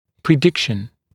[prɪ’dɪkʃn][при’дикшн]прогноз, прогнозирование